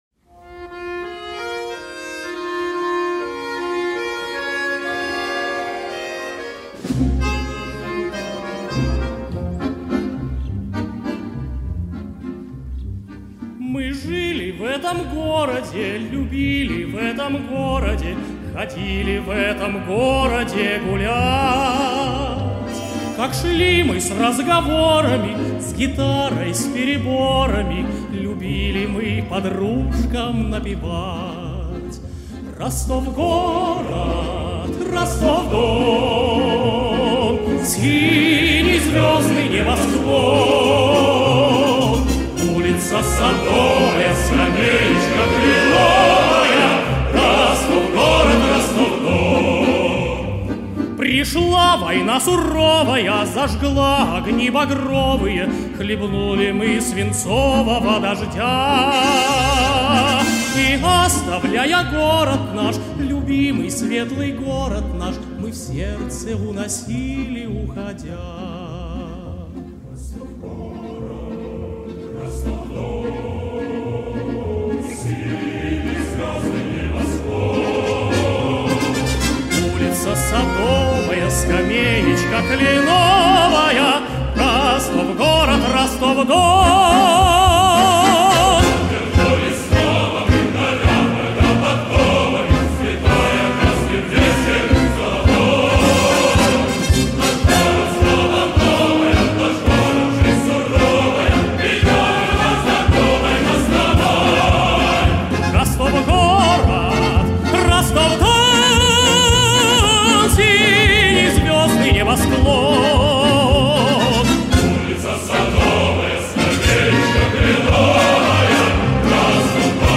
солист